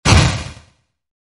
RocketHitWalls.ogg